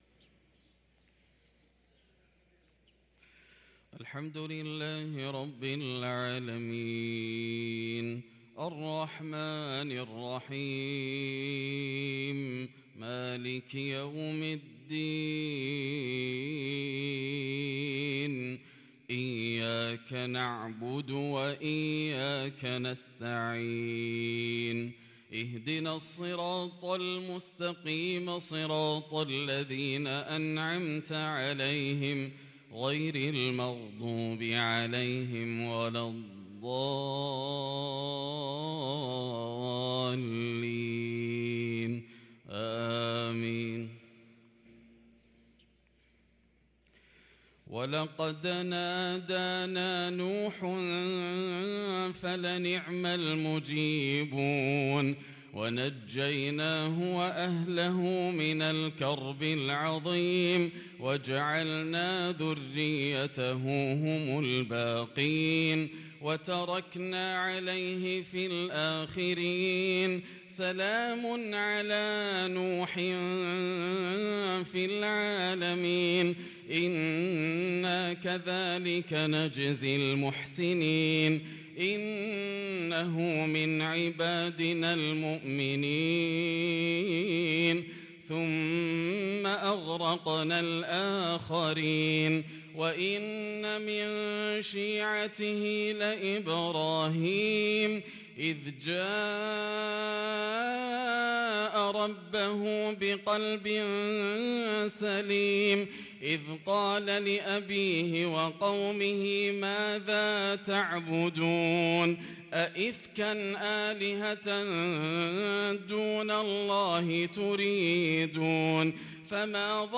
صلاة العشاء للقارئ ياسر الدوسري 7 جمادي الآخر 1444 هـ
تِلَاوَات الْحَرَمَيْن .